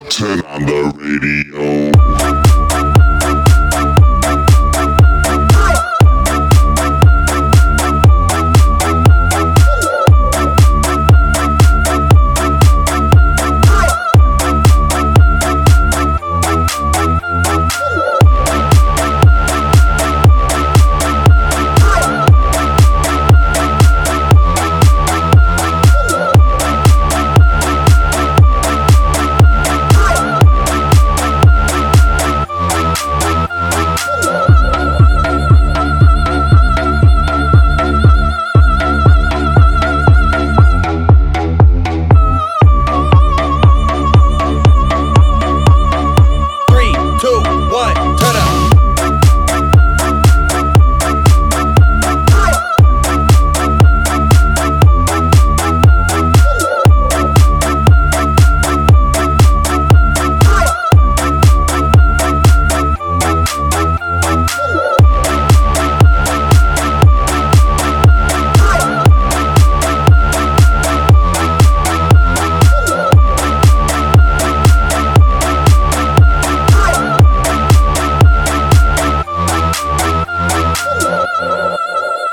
• Качество: 320, Stereo
громкие
качающие
house
динамичные
Крутая хаус-музыка